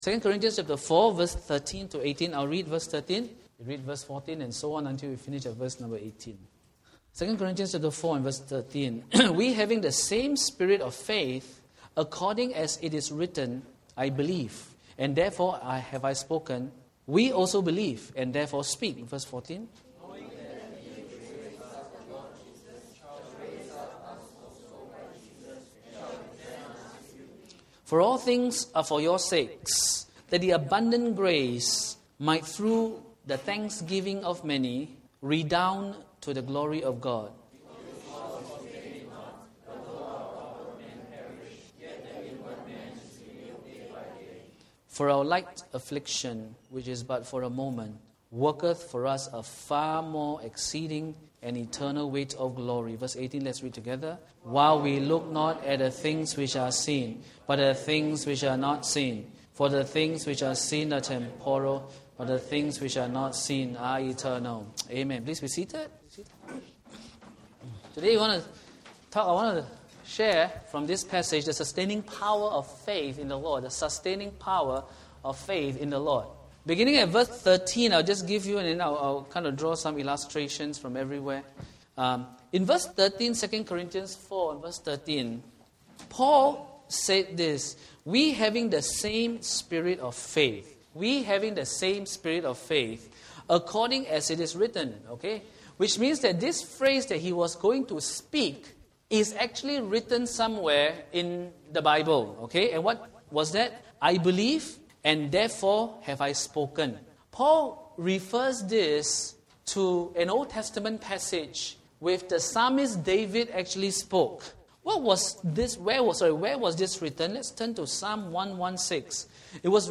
In today’s worship service message, we look at how faith in Christ sustains us. What is faith, and how is it sustained?